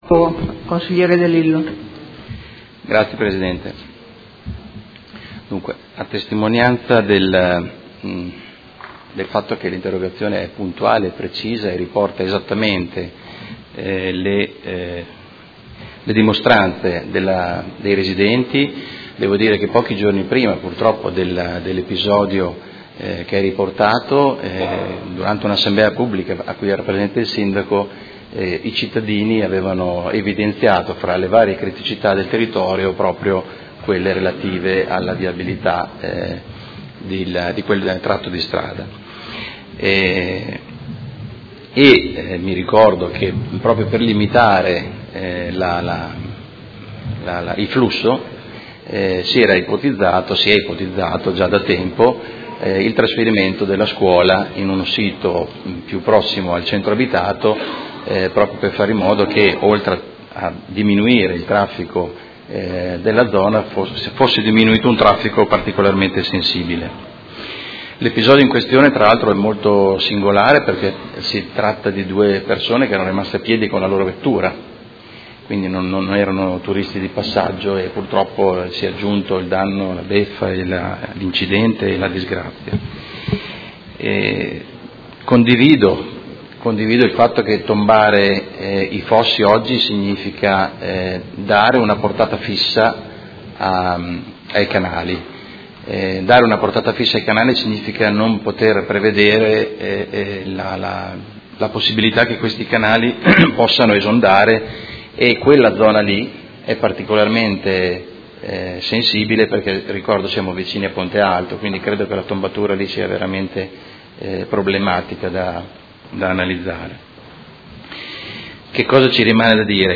Seduta del 21 dicembre 2017